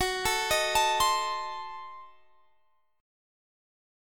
Listen to F#dim7 strummed